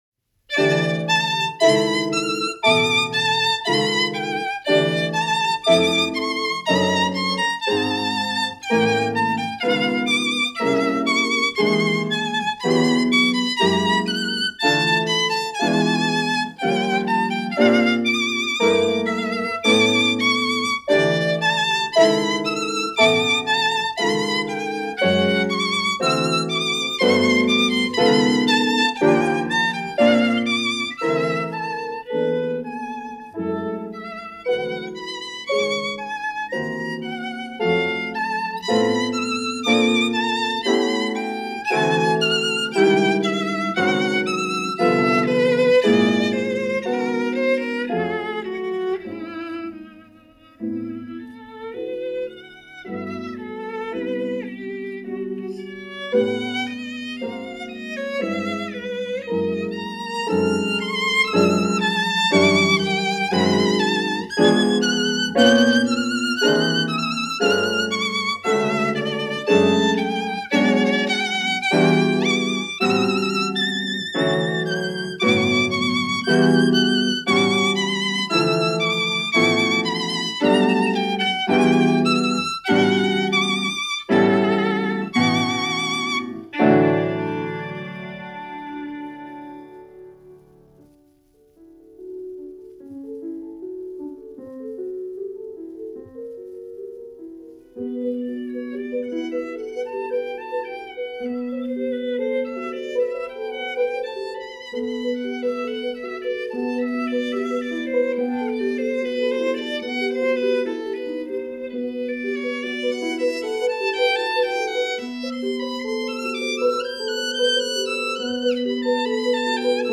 Recorded in performance at the studios of ORTF in Paris
And her work as a musician playing other composers work has gotten her a high degree of respect as a pianist of note.